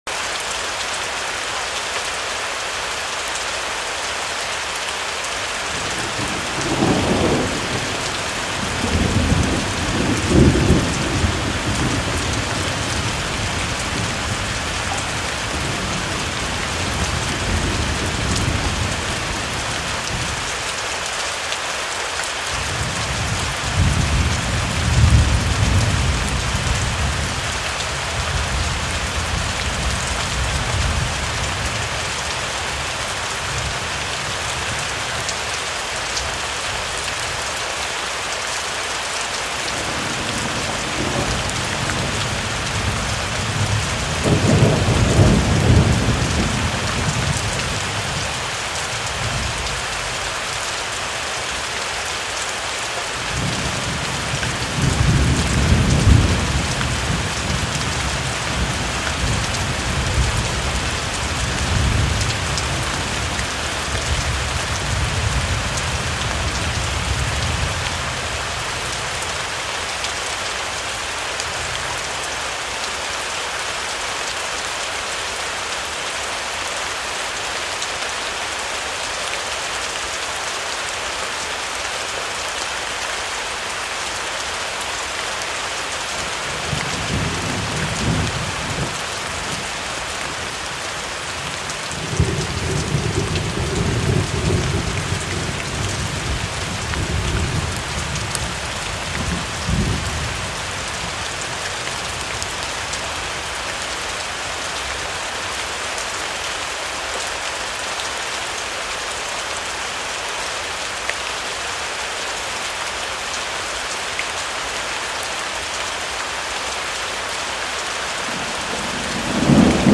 Rain and Thunder for Sleeping sound effects free download
Rain and Thunder for Sleeping - Nature Sounds to Relax and Fall Asleep